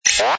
ui_open_infocard_button.wav